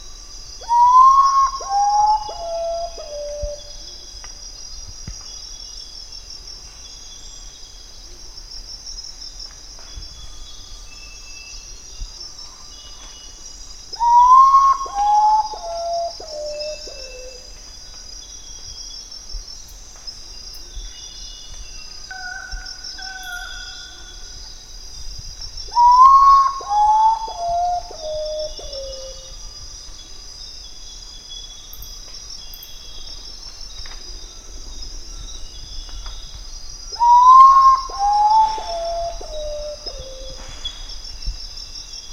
2. Ночной крик козодоя